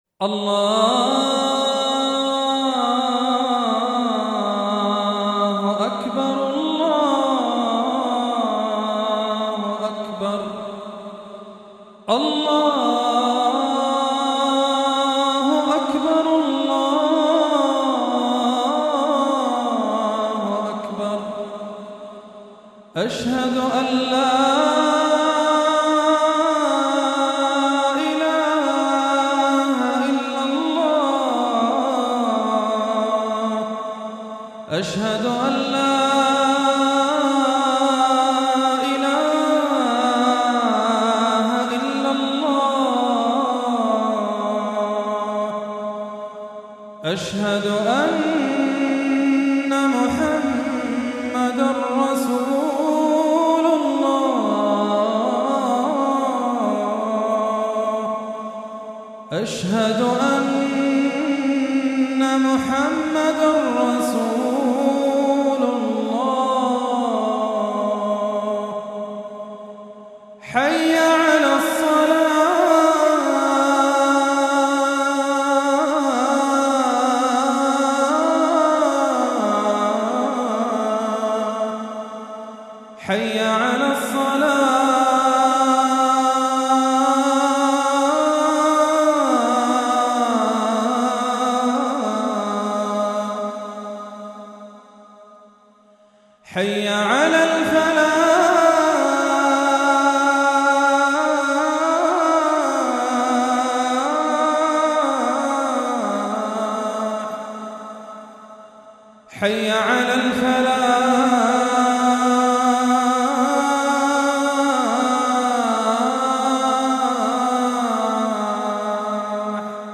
المكتبة الصوتية روائع الآذان المادة آذان